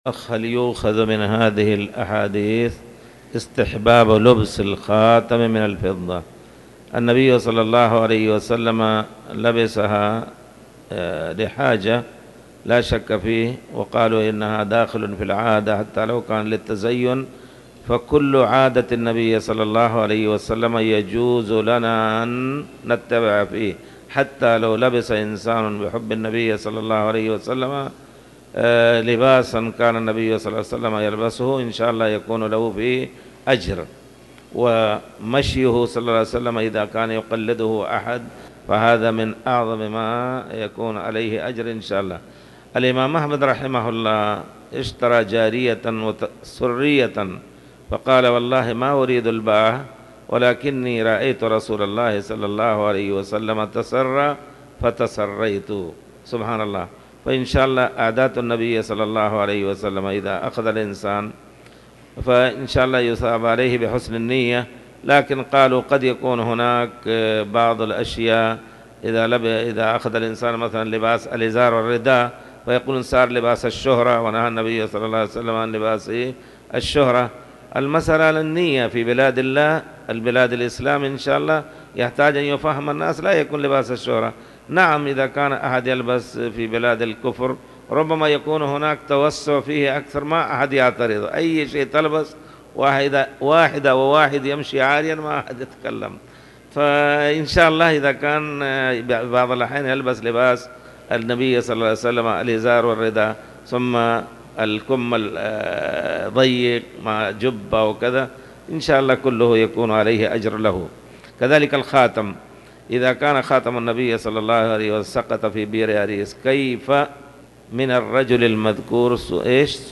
تاريخ النشر ٢ ربيع الثاني ١٤٣٨ هـ المكان: المسجد الحرام الشيخ